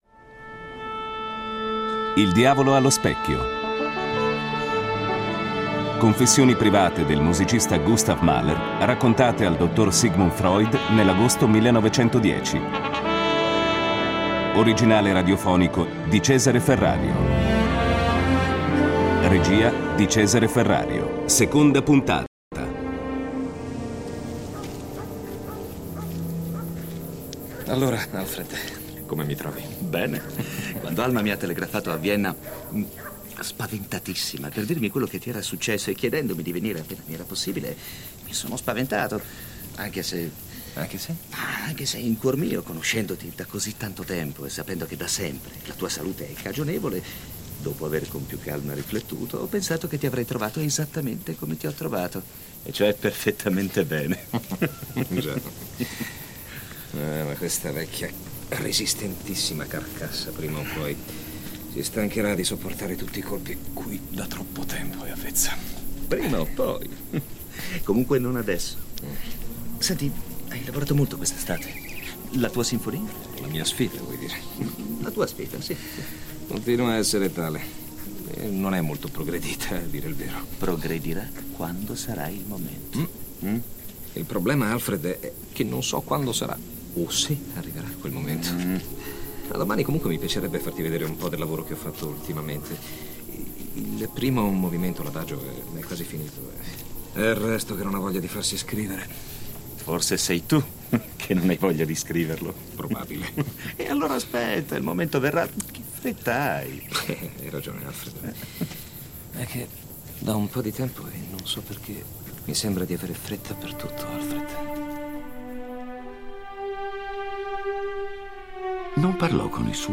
Confessioni private del musicista Gustav Mahler raccontate al dr. Sigmund Freud nell’agosto 1910. Originale radiofonico di Cesare Ferrario